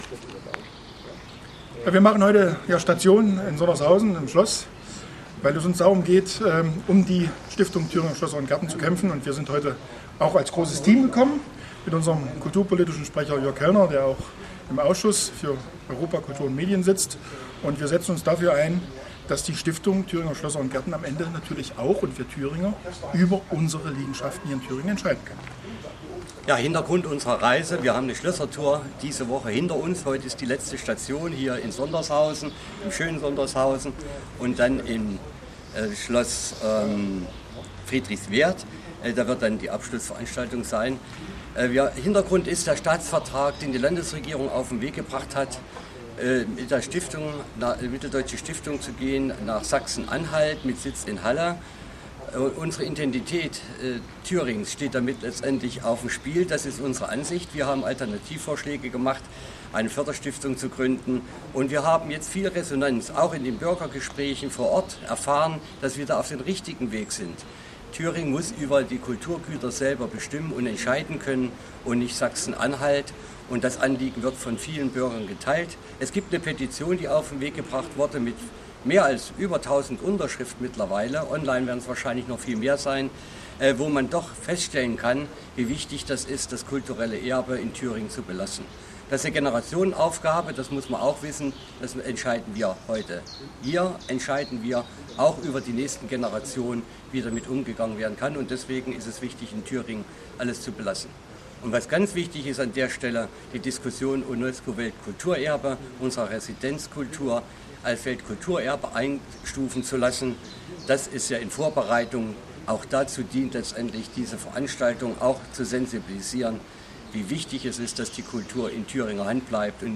Gegenüber kn sagten sie:
Stellungnahme Schard und Kellner